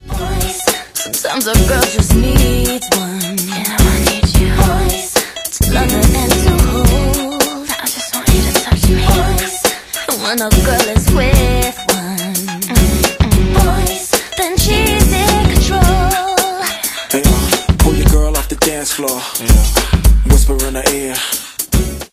Reduced quality: Yes
It is of a lower quality than the original recording.